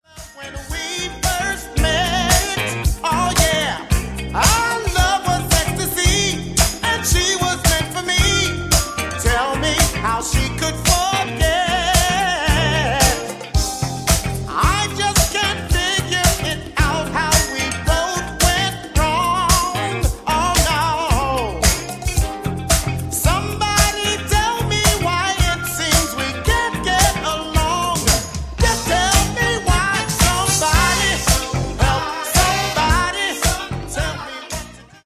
Genere:   Disco | Soul | Funk
12''Mix Extended